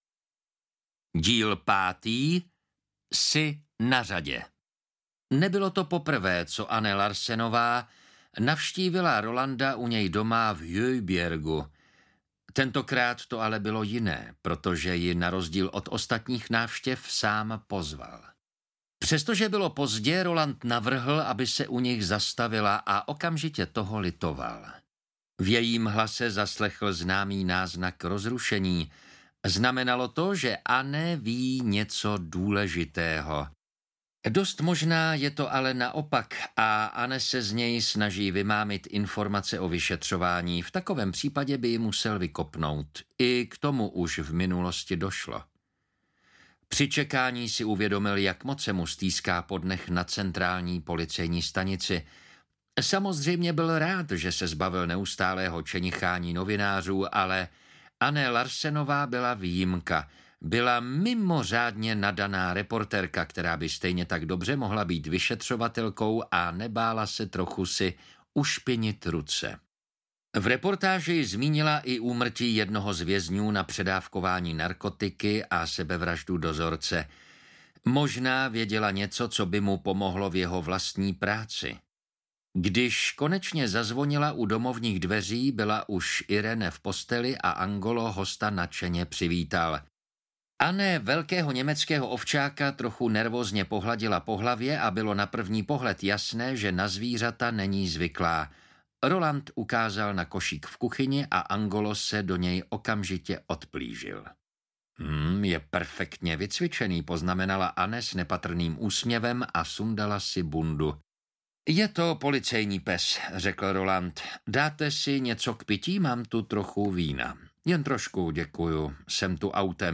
Ukázka z knihy
uklizec-5-jsi-na-rade-audiokniha